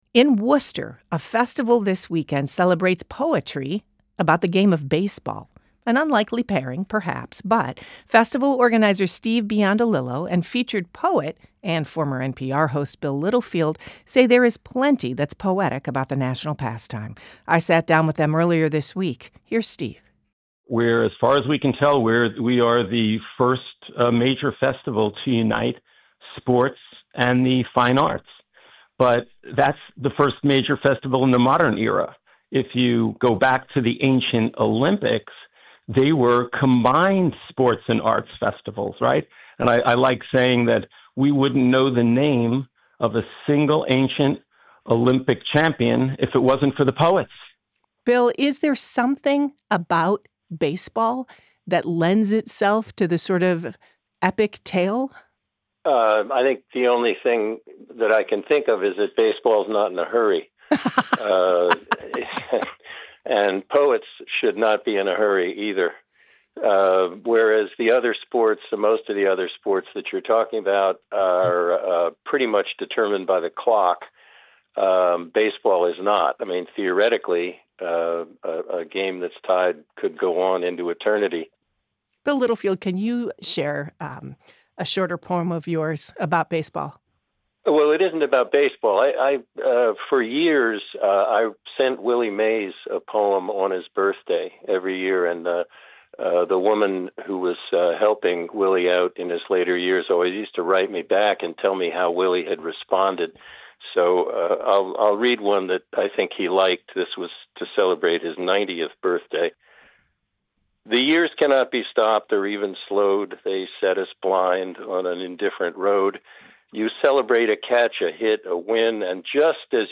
They join WBUR’s Morning Edition.